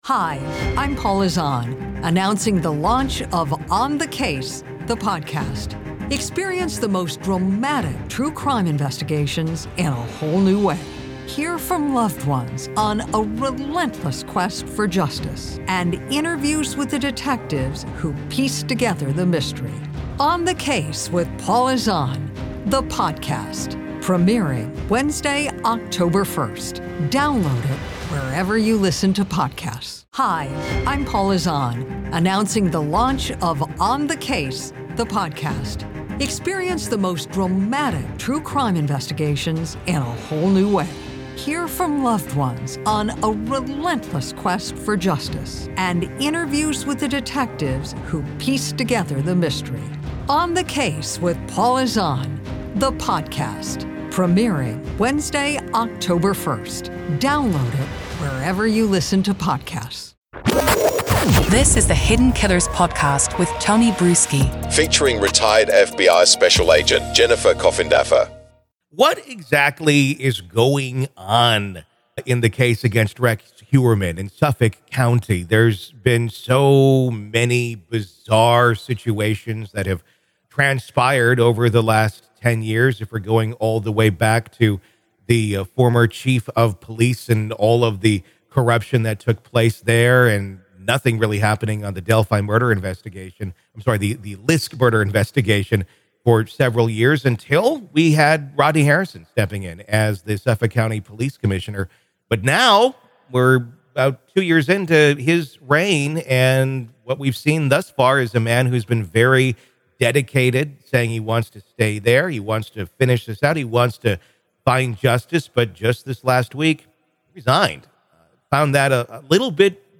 True Crime Today | Daily True Crime News & Interviews / LISK Mystery, Why Did Suffolk County Police Commissioner Rodney Harrison Resign?